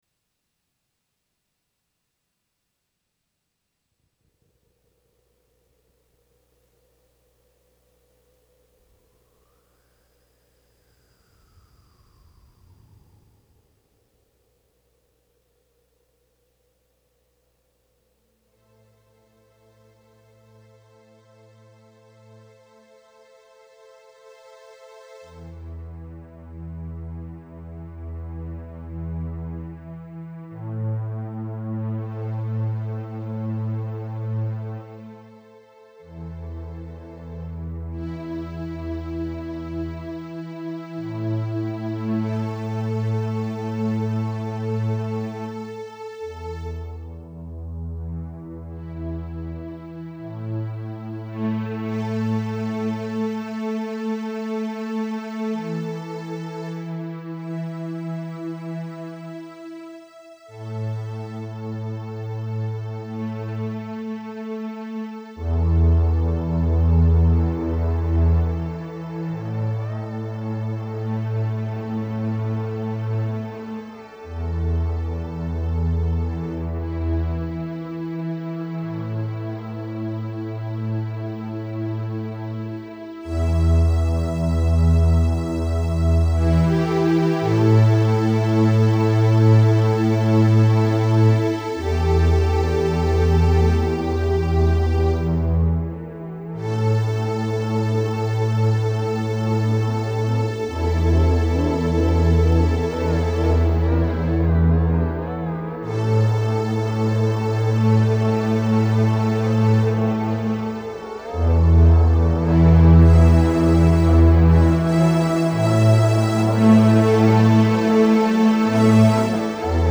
electric guitars